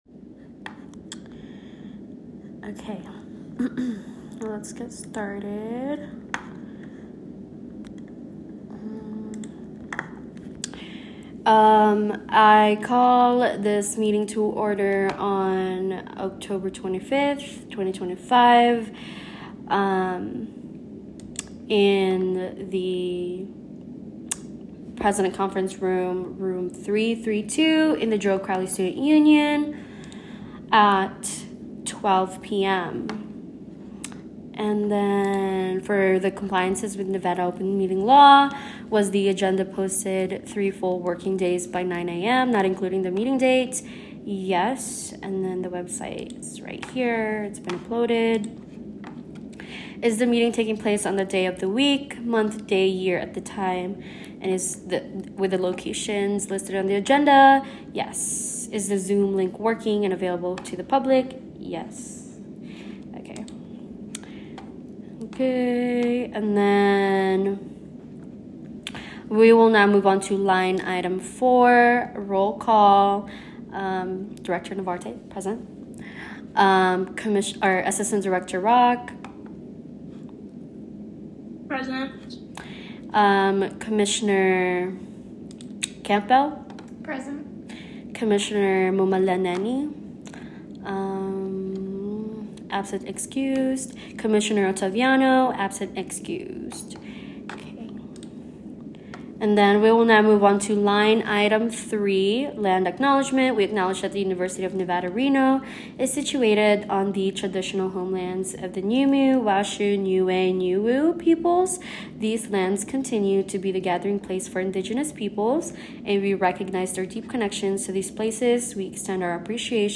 The Department of Inclusion, Diversity, Equity, and Accessibility (IDEA) formal meeting highlights upcoming cultural engagement initiatives, including the Sugar Skulls Workshop and Día de los Muertos Celebration. These events aim to honor Latinx traditions through creative expression, reflection, and community learning.